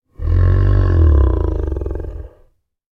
Sound Effects
Boss Noise.wav